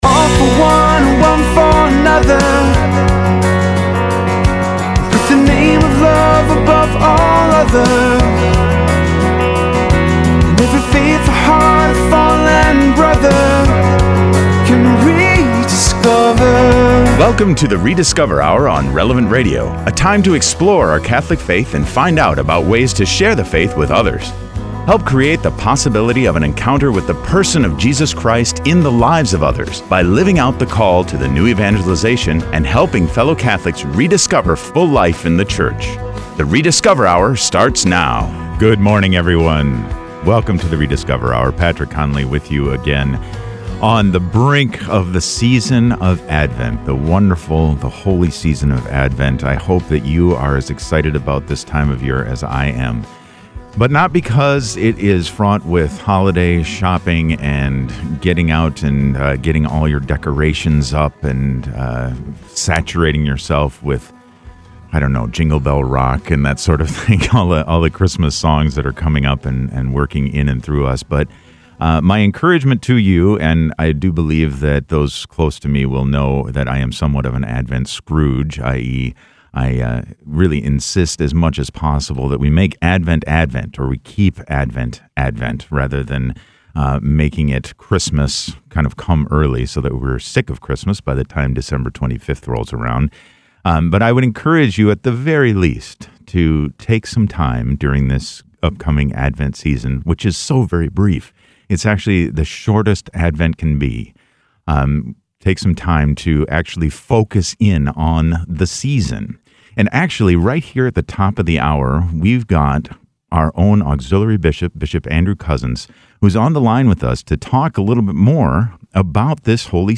Además, el obispo Cozzens se unirá a nosotros al comienzo de la hora para compartir cómo podemos preparar nuestros corazones para el Adviento.